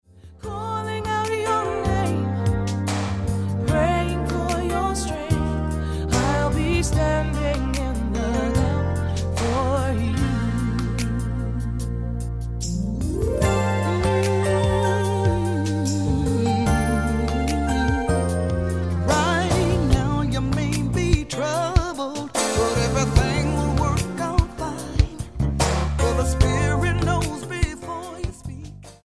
Gospel Album